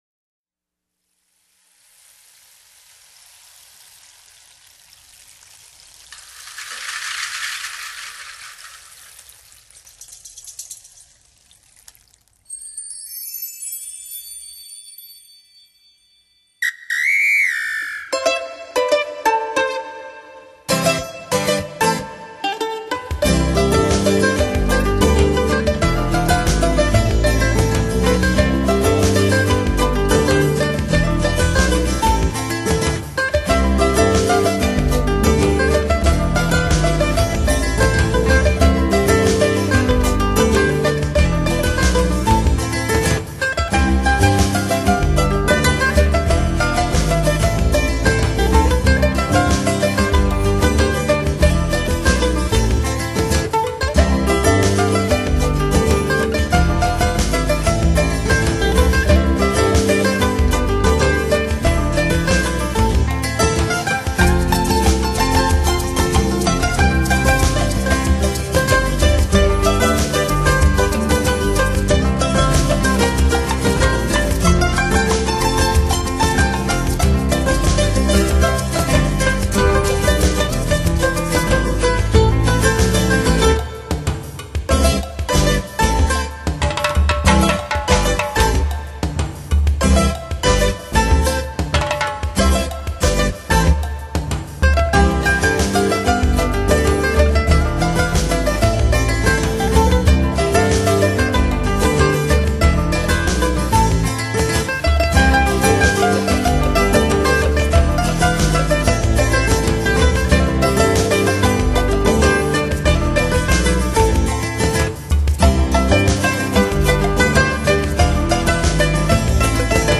本片结合吉普赛人流浪的情结，加上东方的迷幻多情，使整张专辑营造出一幕幕幽远意象，如泣如诉地演奏出感人动听的旋律。